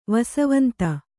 ♪ vasavanta